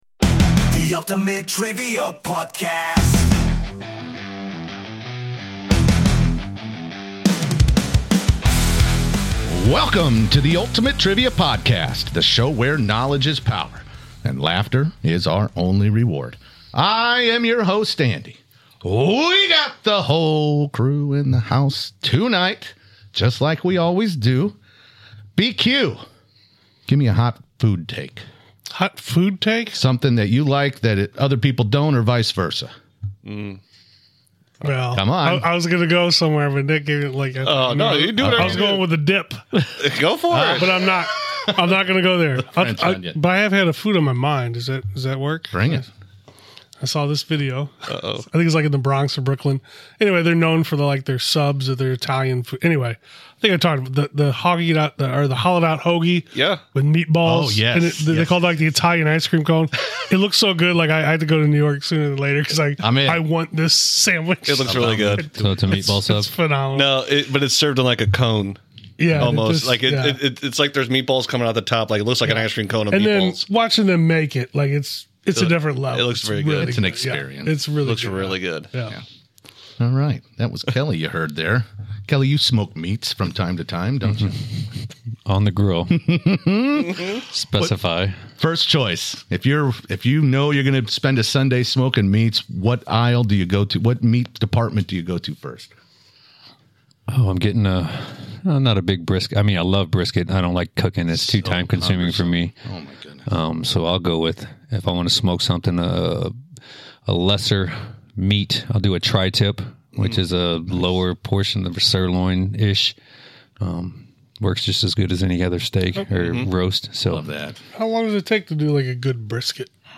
The vibe? Competitive, fast-paced, and always entertaining. Each week, they tackle 10 well-balanced questions across history, science, pop culture, and more—mixing in fascinating facts, fierce competition, and the kind of banter only true friends (and rivals) can deliver.